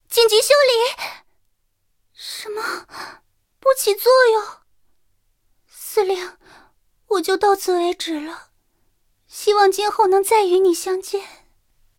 三号被击毁语音.OGG